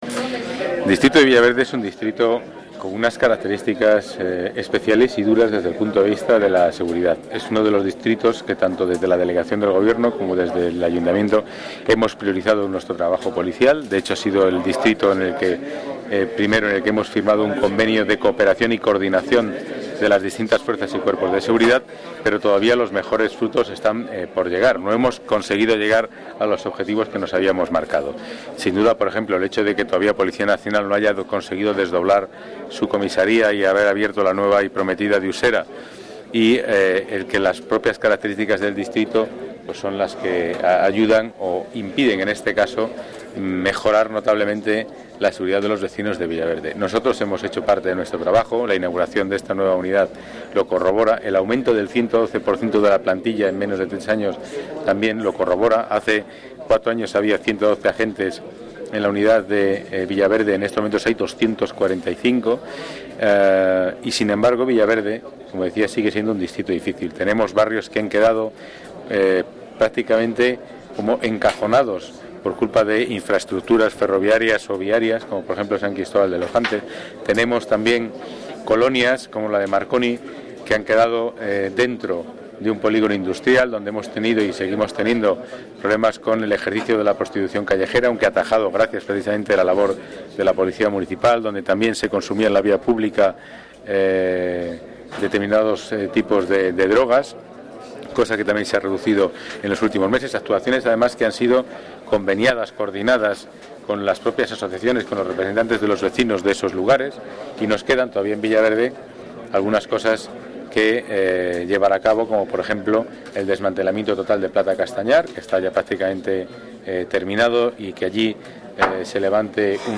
Nueva ventana:Pedro Calvo analiza la situación del distrito de Villaverde en la inauguración de la nueva sede de la Unidad Integral de la Policía Municipal